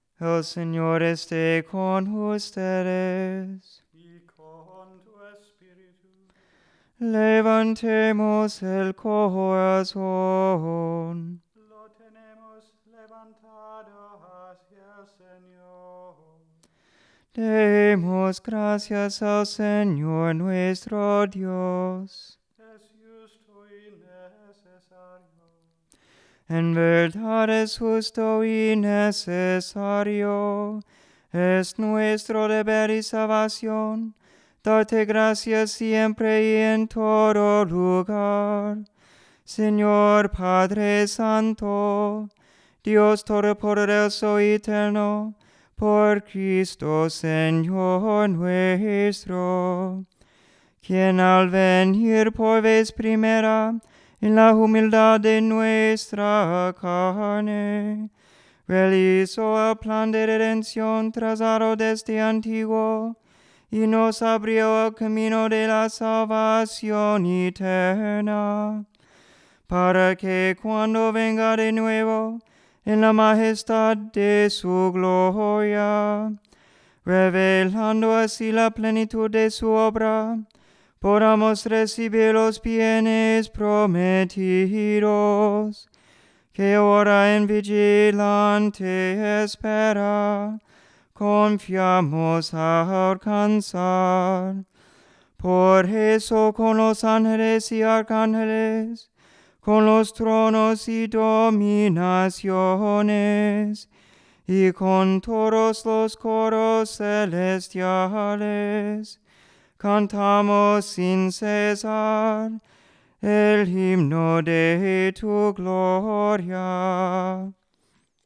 Sung Preface Example